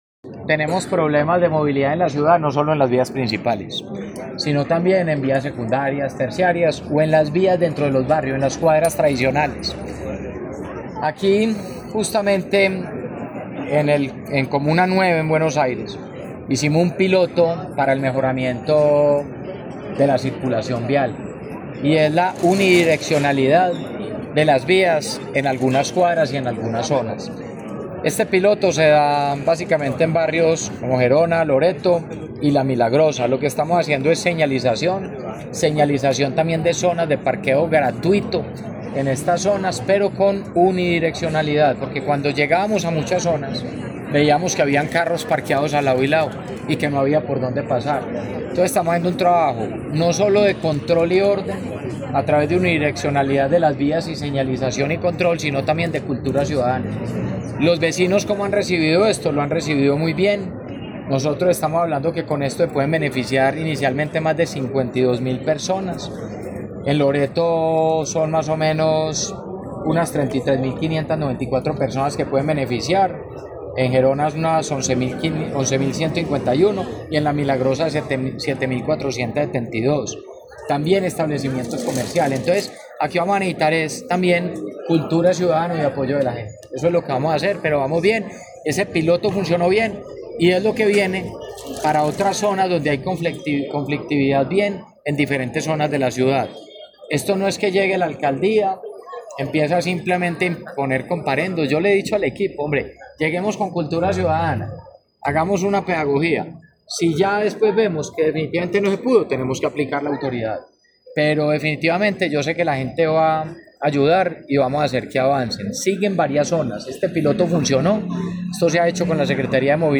Declaraciones-alcalde-de-Medellin-Federico-Gutierrez-Zuluaga-2.mp3